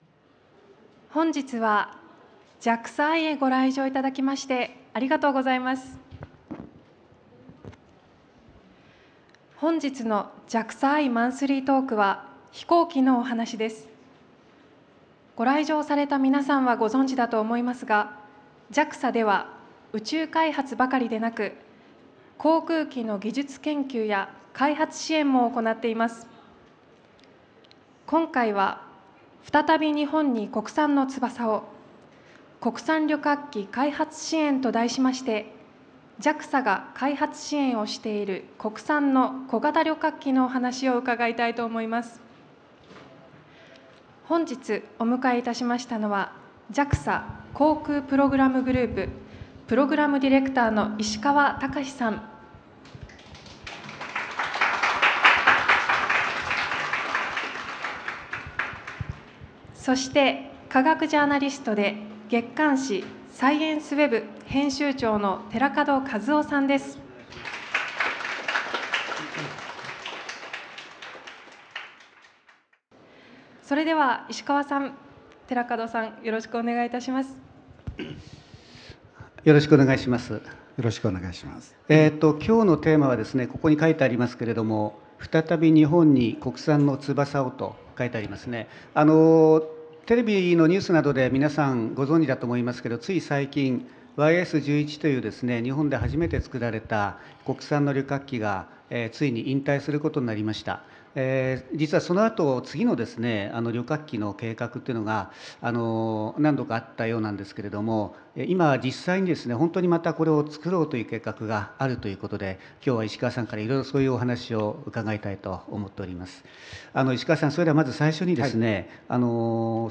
聞き手 ：科学ジャーナリスト